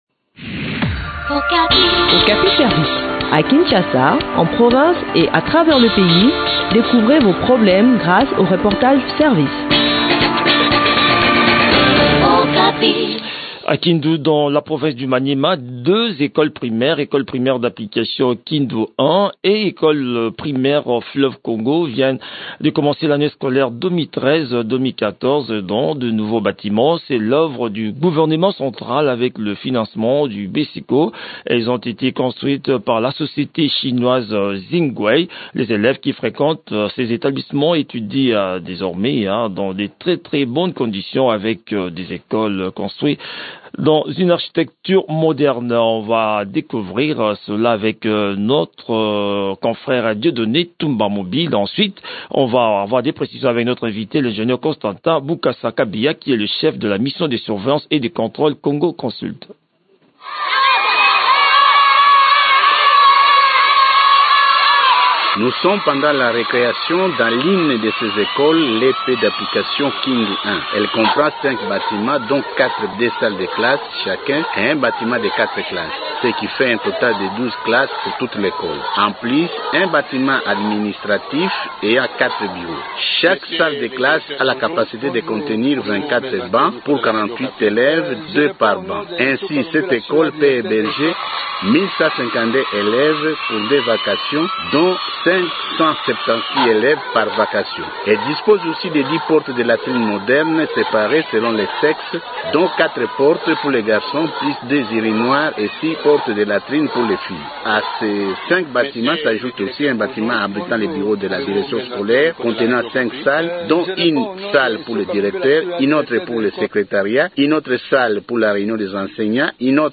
Le point sur l’exécution de ce programme dans cet entretien